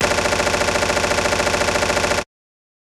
Roland.Juno.D _ Limited Edition _ GM2 SFX Kit _ 15.wav